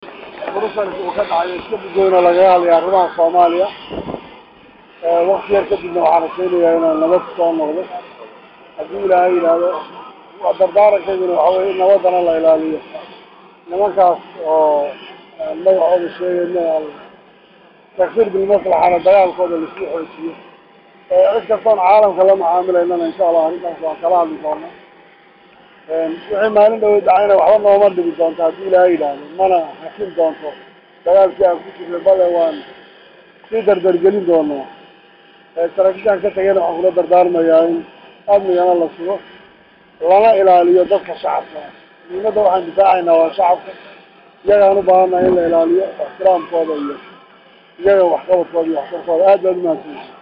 Madaxweyne Axmed Madowbe mar uu lahadlay saxafada ayaa waxa uu yaga waramay socdaal kiisa iyo halaka uu ku wajahanyahay.